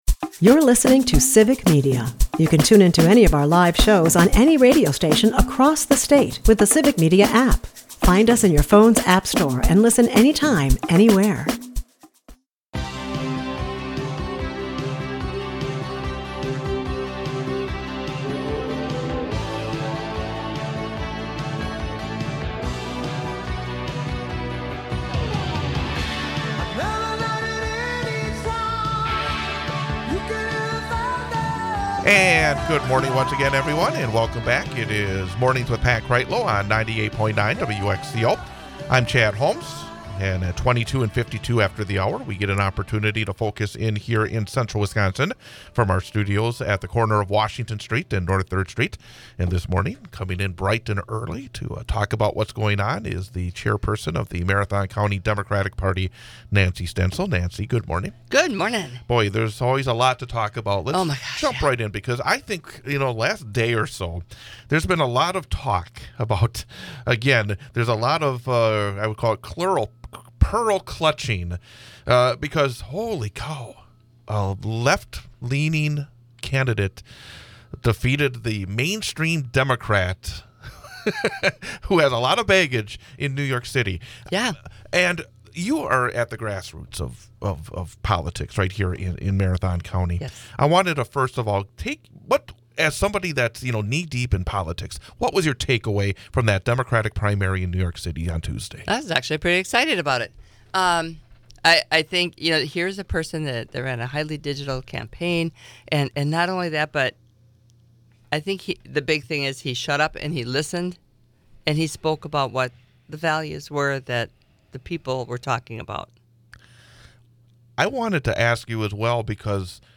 We are joined in studio